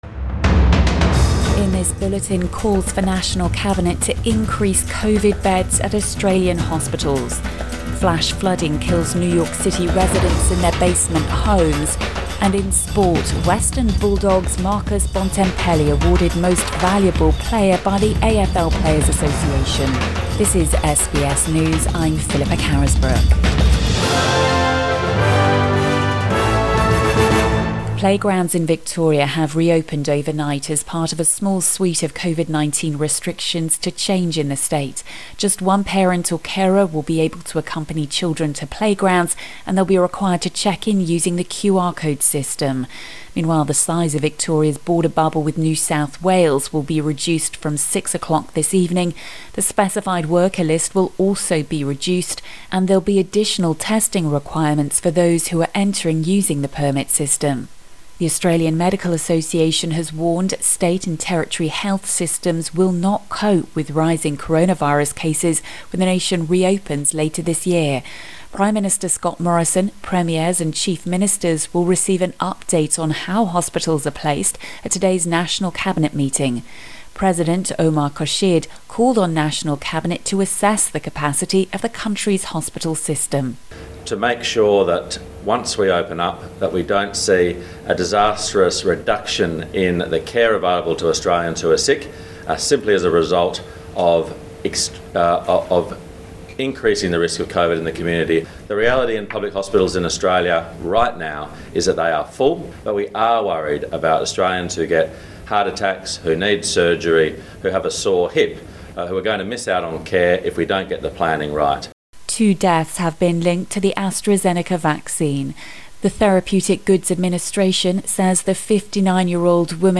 AM bulletin 3 September 2021